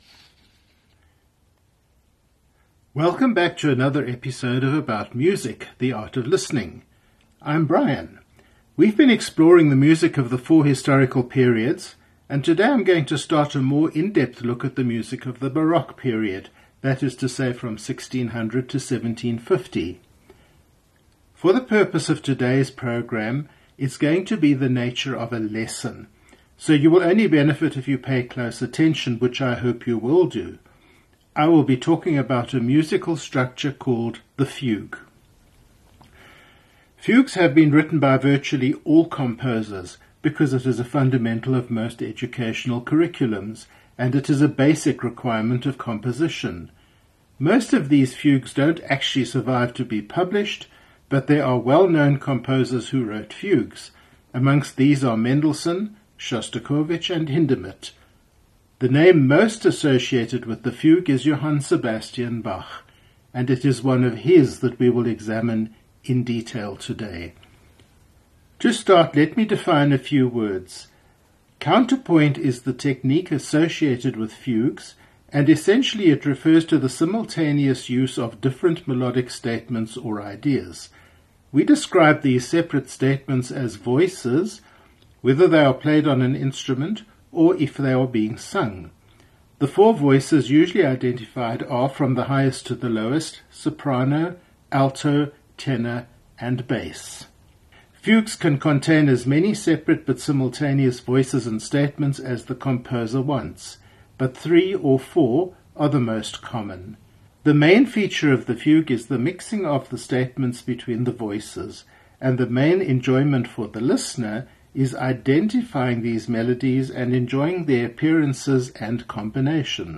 Johan Sebastian Bach: The Well-Tempered Clavier, Book 1: Preludes and Fugues 1 – 8, BWV 846 – 853 and BWV 866 Performed by Angela Hewitt